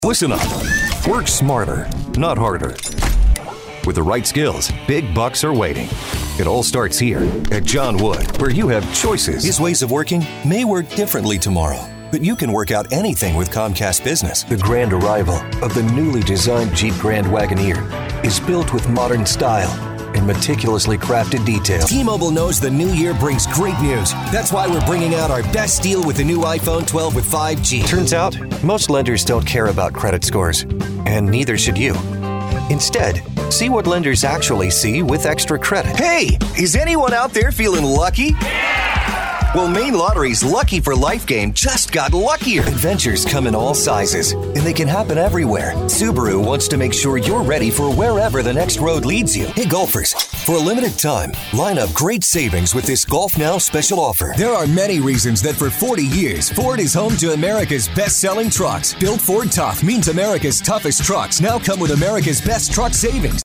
Announcer , Male , Professional Description Description http